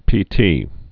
(pē-tē)